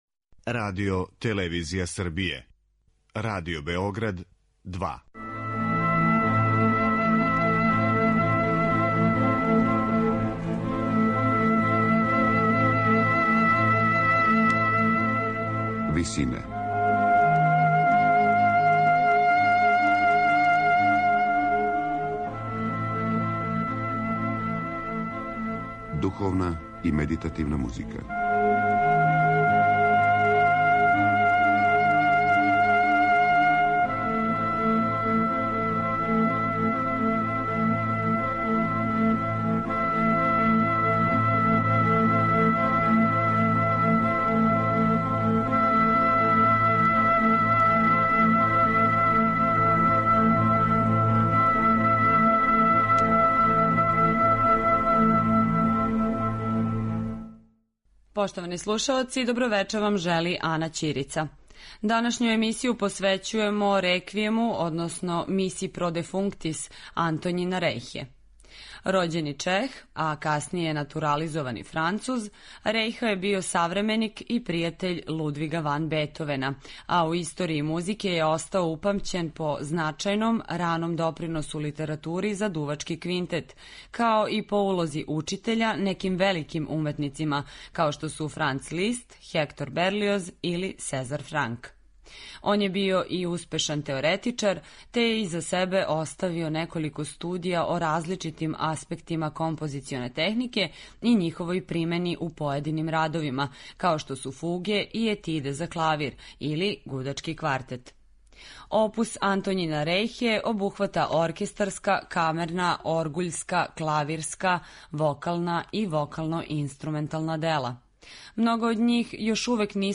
Missa pro defunctis за солисте, оргуље, хор и оркестар, настала је између 1802. и 1808. године, а стилски и временски је позиционирана између монументалних Реквијема Моцарта и Берлиоза...